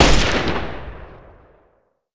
wpn_riflelincolns.wav